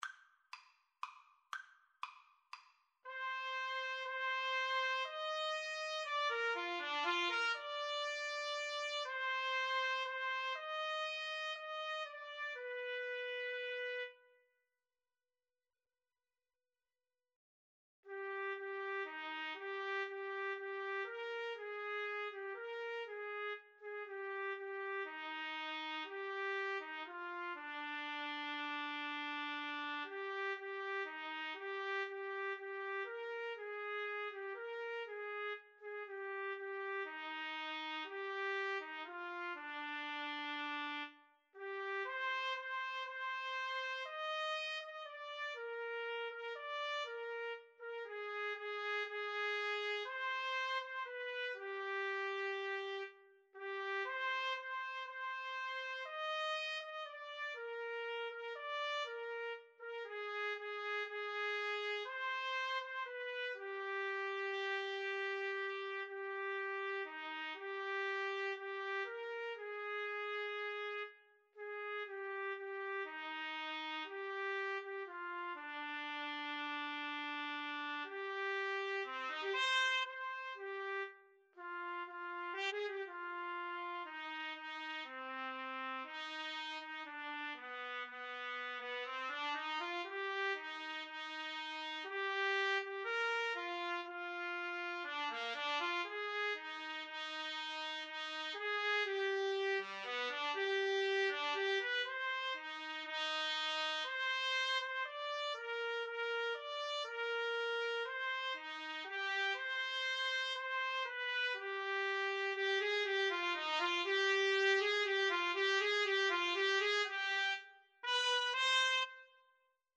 3/4 (View more 3/4 Music)
Slow Waltz .=40